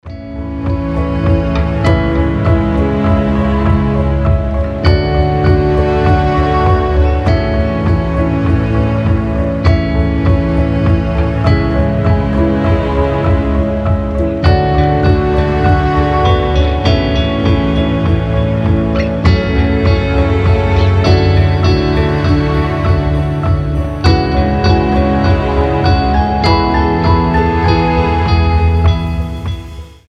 • Качество: 320, Stereo
без слов
красивая мелодия
Ambient
пост-рок
Синематический пост-рок с преобладающим звучанием эмбиента